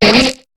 Cri de Ningale dans Pokémon HOME.